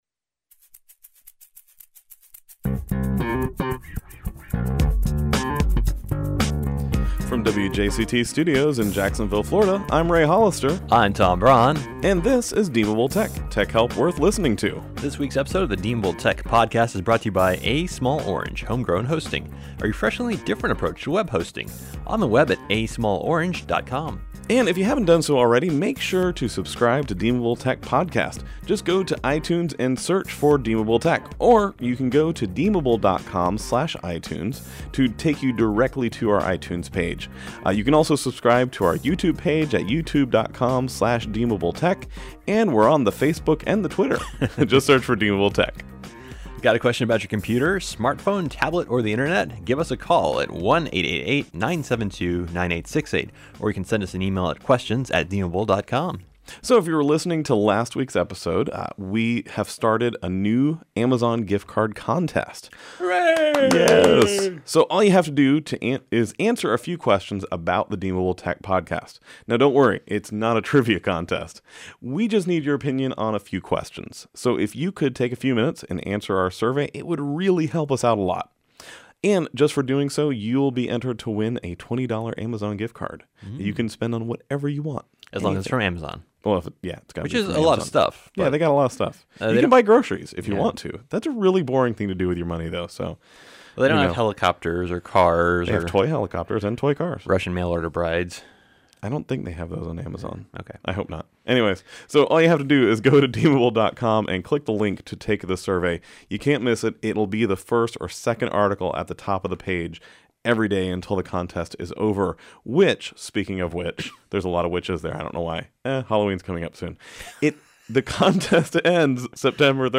This week on Deemable Tech, we’re really back in the studio, and we answer tech questions! We have questions about how to wipe an iPad, whether you should buy an AMD or an Intel laptop, the best way to host unique email addresses, and more.